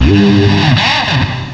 cry_not_abomasnow.aif